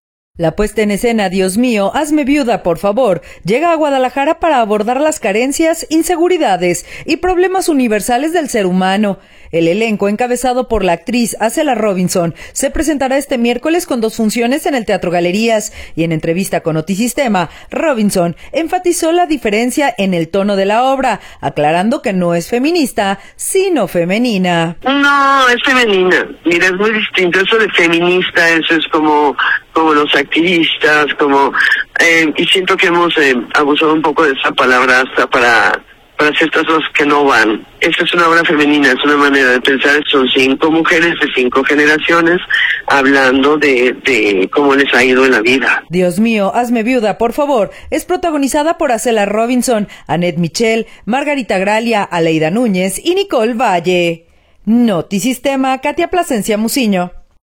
El elenco, encabezado por la actriz Azela Robinson, se presentará este miércoles con dos funciones en el Teatro Galerías y en entrevista con Notisistema, Robinson enfatizó la diferencia en el tono de la obra, aclarando que no es feminista, sino “femenina”.